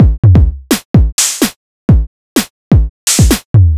Chunk Funk Beat 127.wav